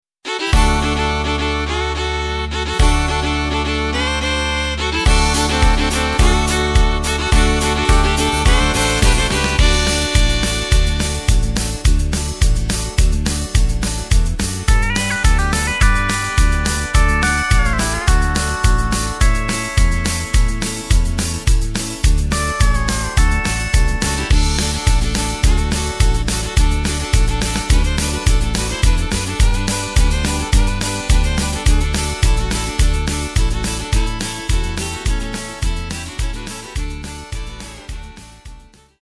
Takt:          2/4
Tempo:         106.00
Tonart:            D
Country Song!
Playback mp3 Demo